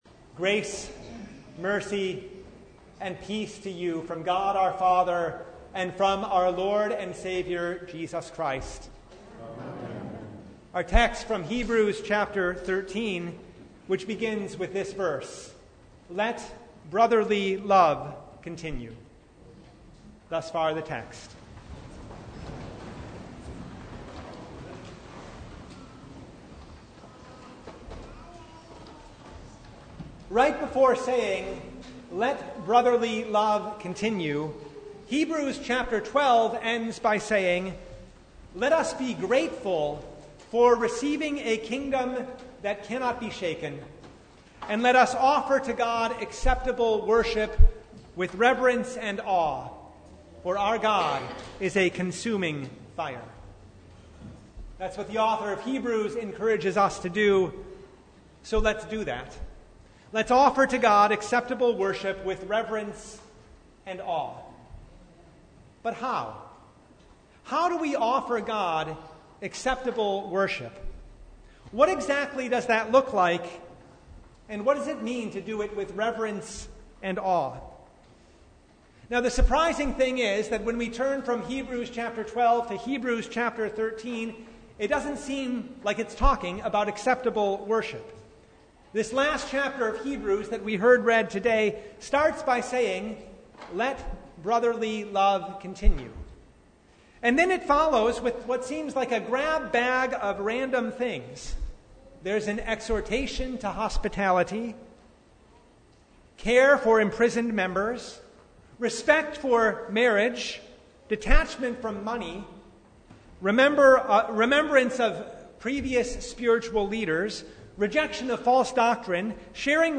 Sermon from The Fourth Sunday in Martyrs’ Tide (2022)